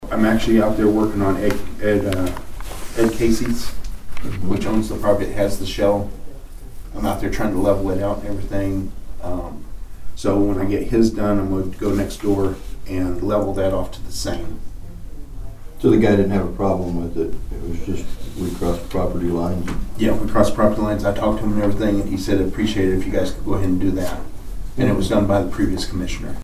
The Nowata County Commissioners held a regularly scheduled meeting at the Nowata County Annex on Monday morning.
Chairman Paul Crupper discussed the agreement.